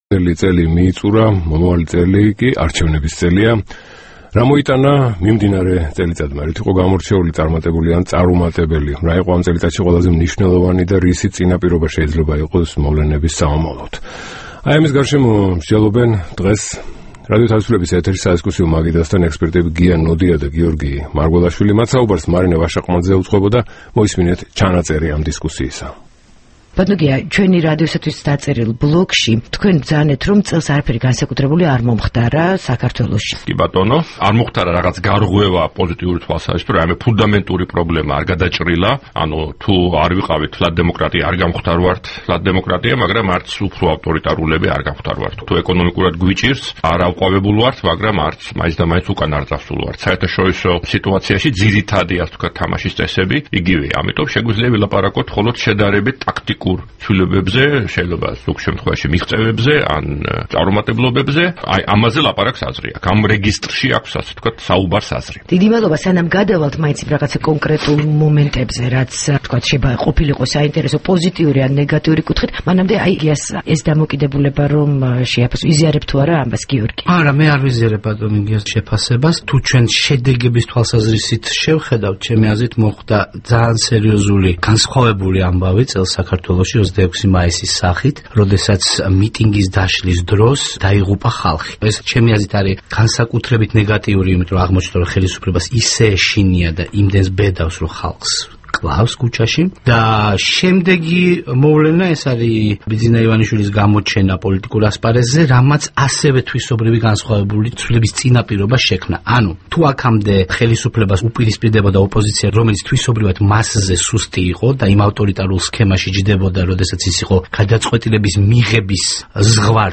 საუბარი გიორგი მარგველაშვილთან და გია ნოდიასთან